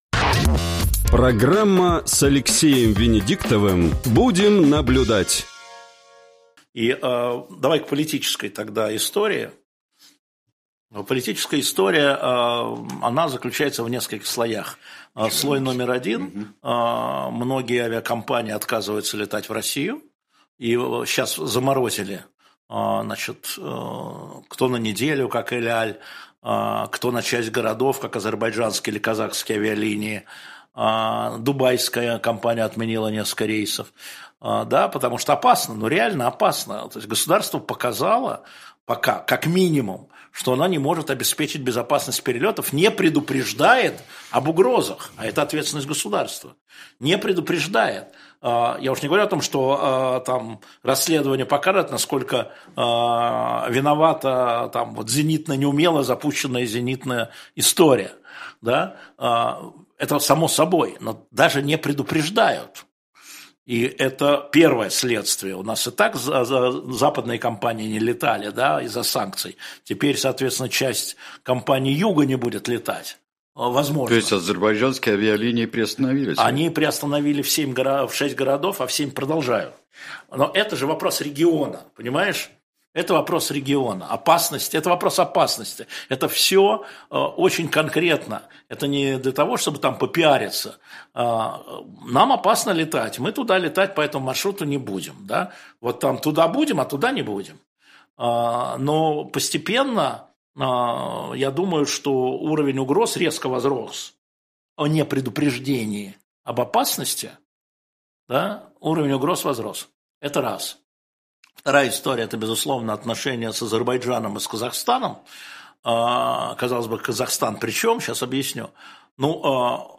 Алексей Венедиктовжурналист
Сергей Бунтманжурналист
Фрагмент эфира от 28.12.24